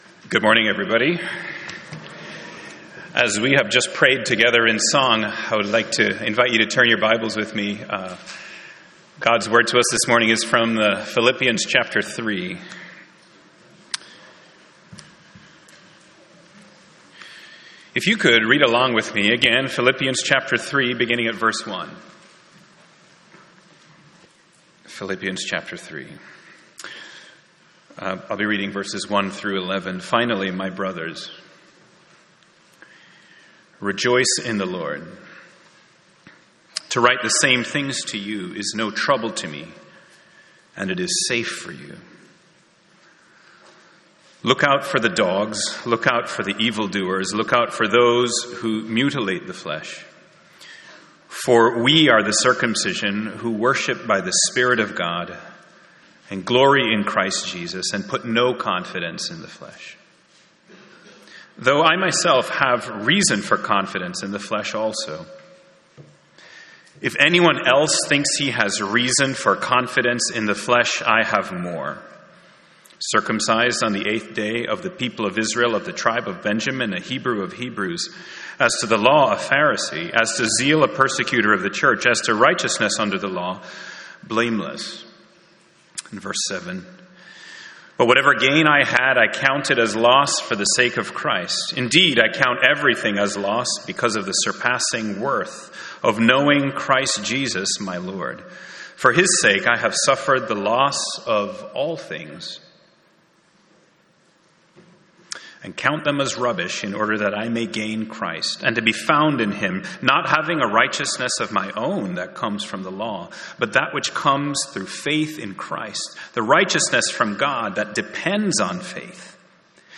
Sermon Outline 1. A false teaching The danger of putting confidence in the flesh 2.